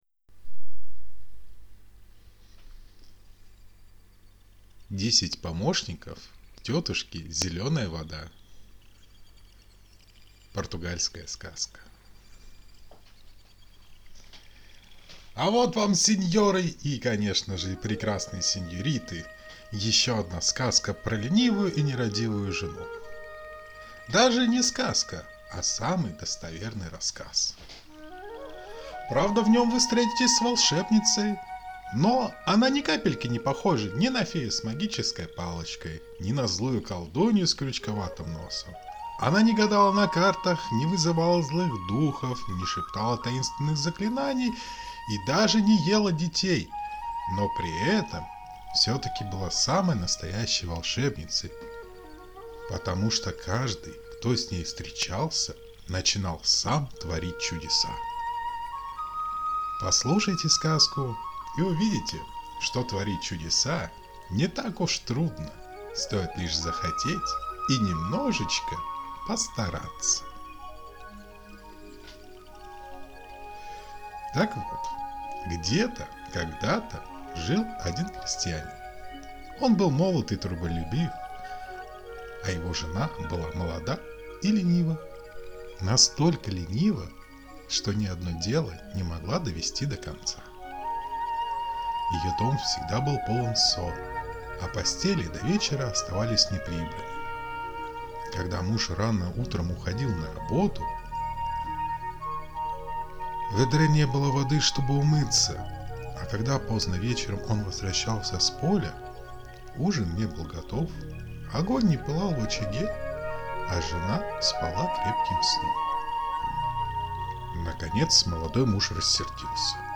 Аудиосказка «Десять помощников тётушки Зелёная Вода»